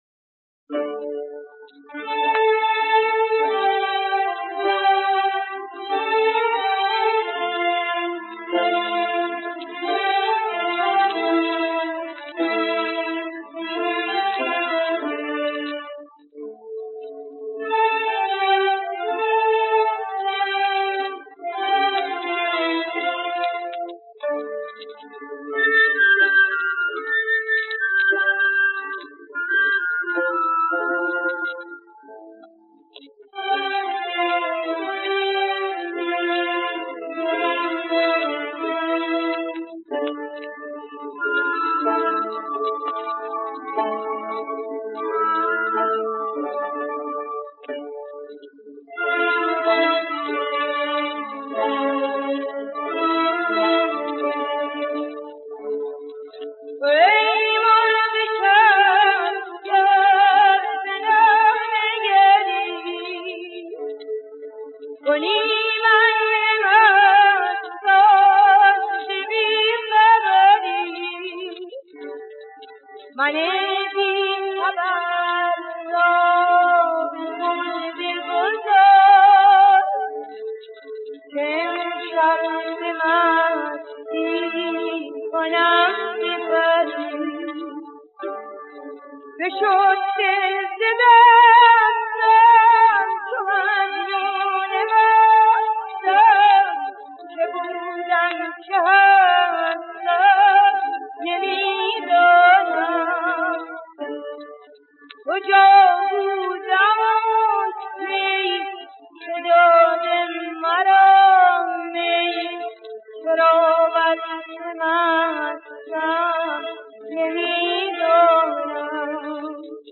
دستگاه: بیات اصفهان